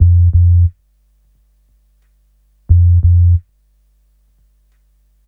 HP089BASS1-L.wav